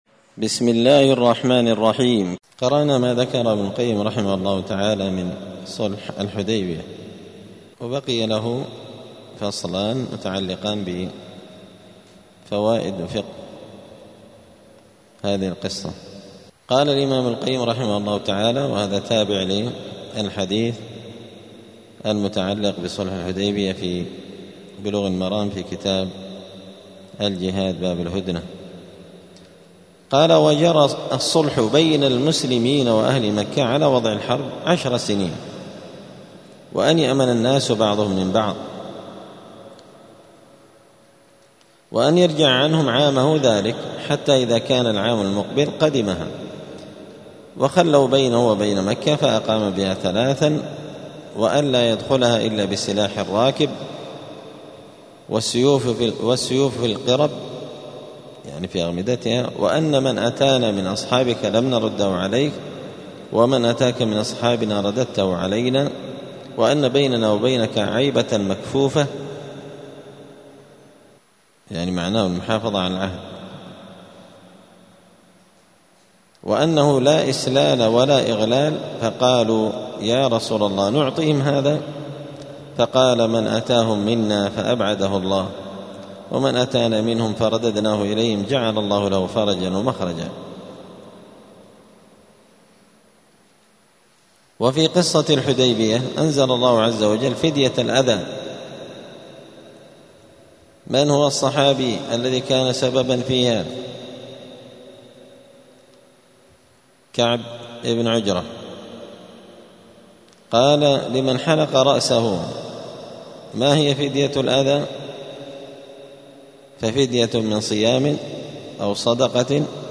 *الدرس التاسع والعشرون (29) {باب ﺗﺤﺮﻳﻢ اﺑﺘﺪاء اﻟﻤﺴﻠﻢ ﻟﻠﻴﻬﻮﺩﻱ ﻭاﻟﻨﺼﺮاﻧﻲ ﺑﺎﻟﺴﻼﻡ}*
دار الحديث السلفية بمسجد الفرقان قشن المهرة اليمن